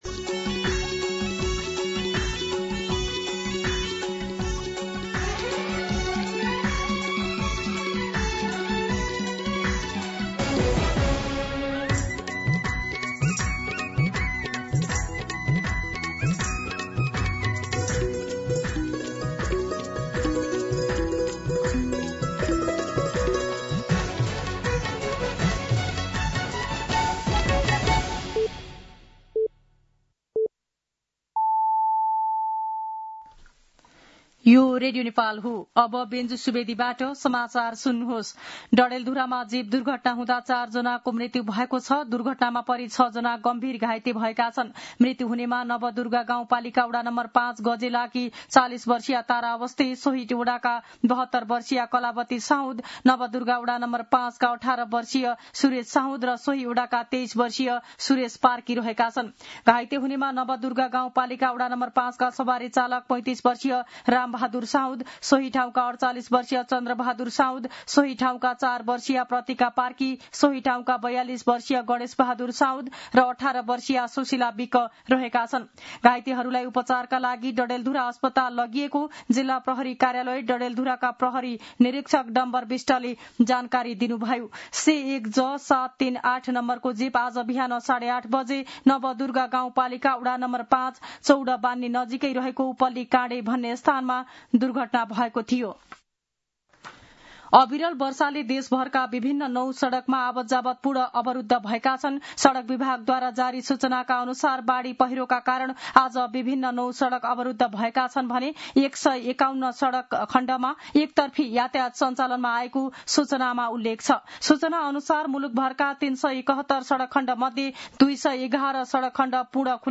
दिउँसो १ बजेको नेपाली समाचार : १२ भदौ , २०८२